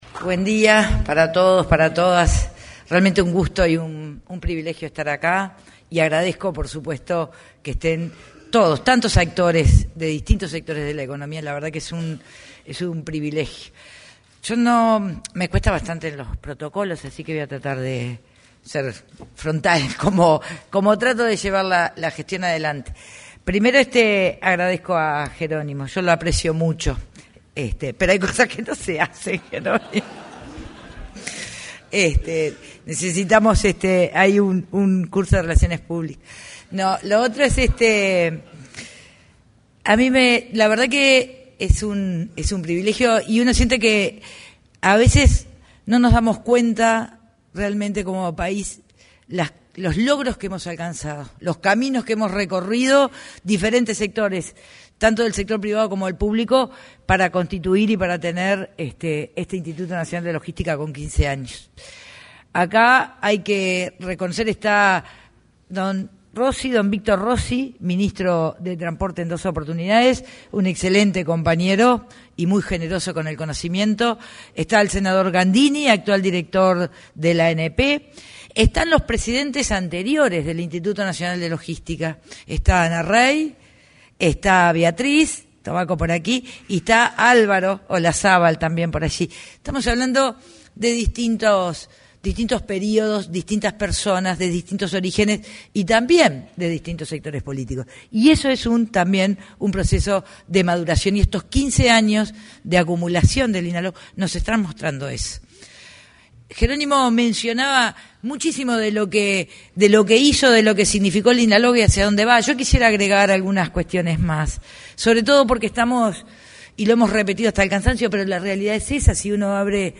Palabras de la ministra de Transporte y Obras Públicas, Lucía Etcheverry
El martes 11 de noviembre, en el auditorio del anexo a la Torre Ejecutiva, se conmemoró el 15.° aniversario del Instituto Nacional de Logística.
En la oportunidad, se expresó la ministra de Transporte y Obras Públicas, Lucía Etcheverry.